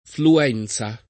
[ flu- $ n Z a ]